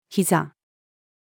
膝-female.mp3